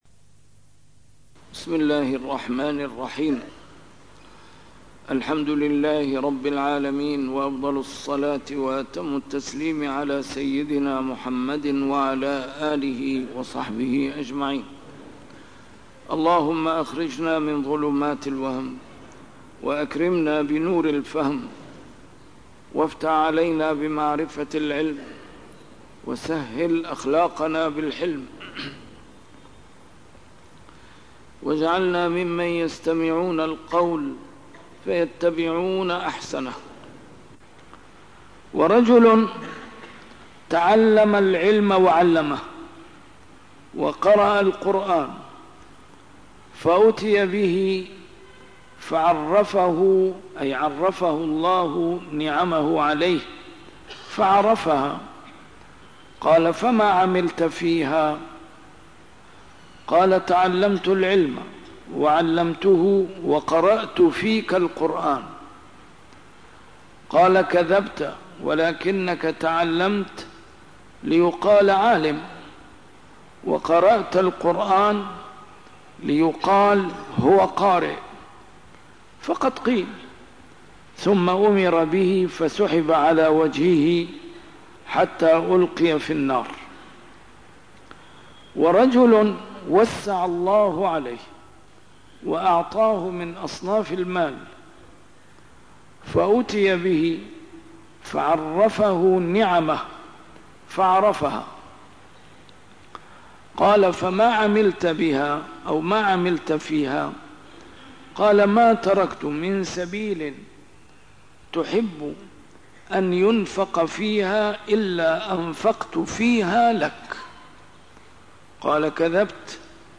شرح كتاب رياض الصالحين - A MARTYR SCHOLAR: IMAM MUHAMMAD SAEED RAMADAN AL-BOUTI - الدروس العلمية - علوم الحديث الشريف - 921- شرح رياض الصالحين: تحريم الرياء